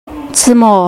wnmj-normal/Resources/Games/WNMJ/WanNianMJ/Woman/zimo0.mp3 at main